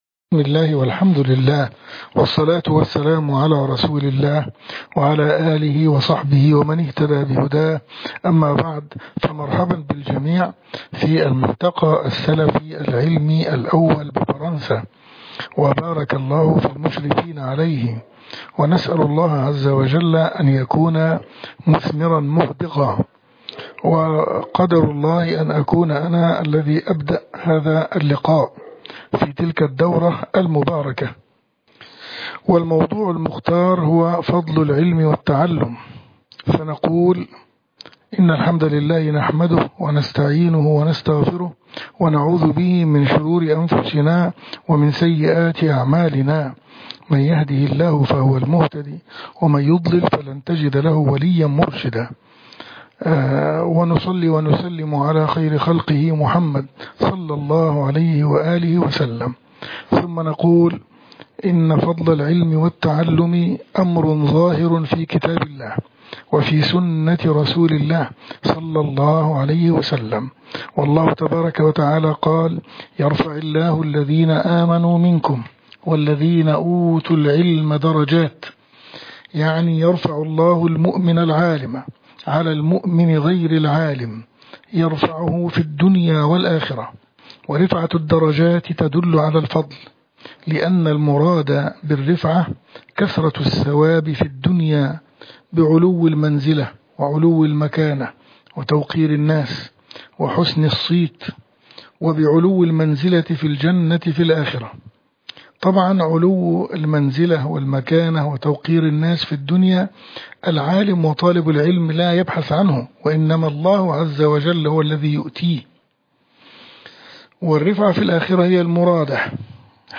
محاضرات وكلمات